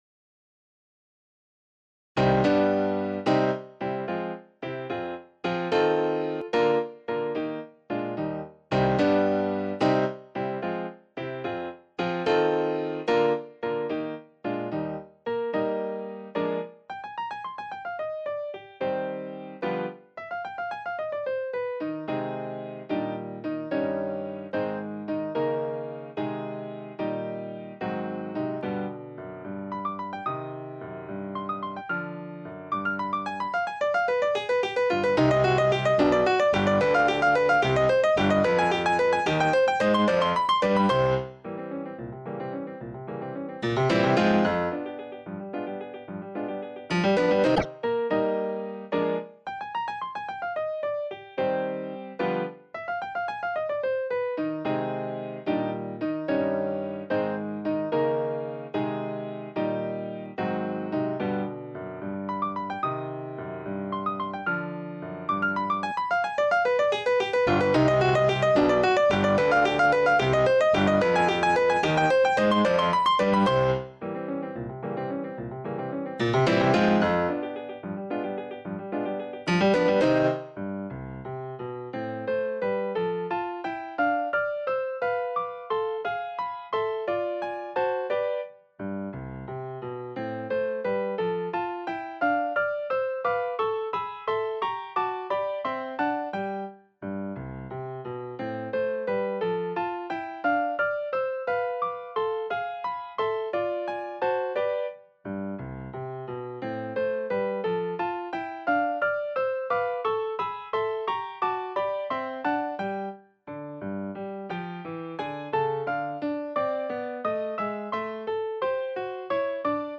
in C minor, for piano